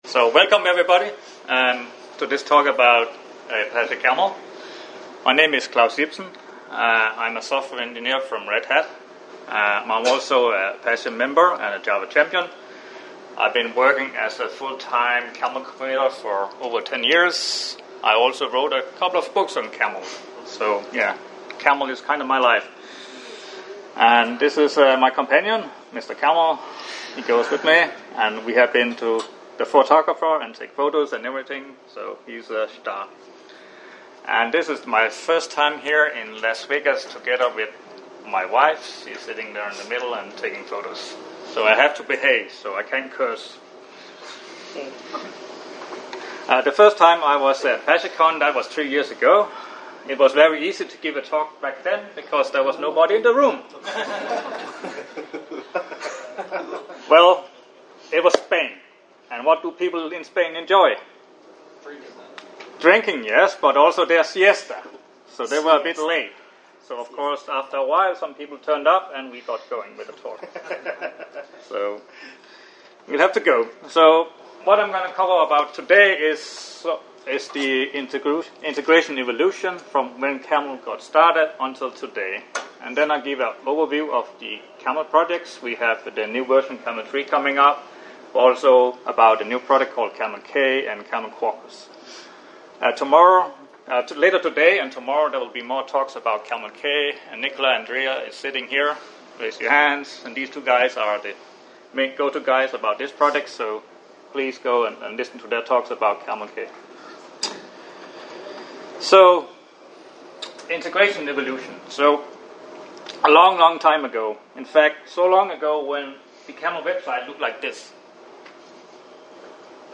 Apache Camel is the leading open source integration framework, which has been around for over a decade. In this talk we will look back in history, to understand how the integration landscape has evolved from EAI, SOA, and ESB architectures up to microservices, and now with modern serverless and cloud native platforms.